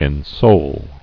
[en·soul]